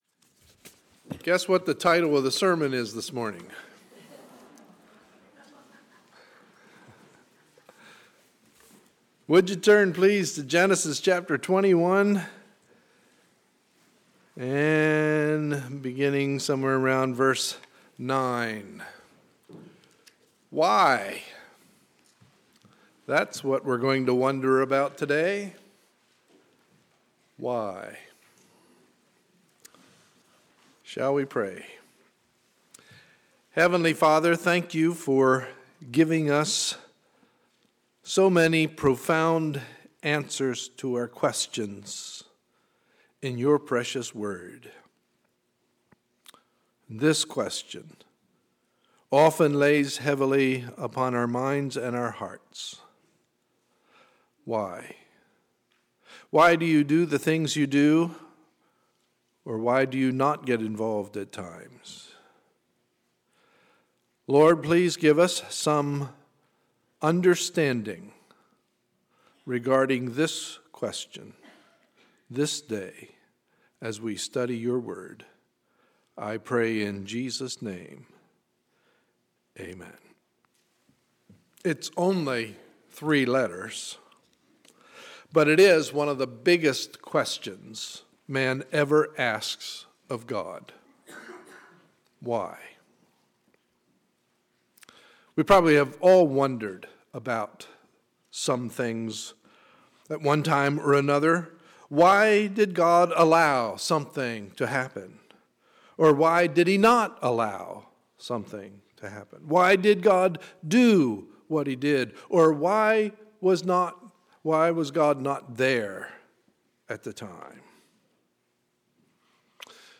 Sunday, February 10, 2013 – Morning Message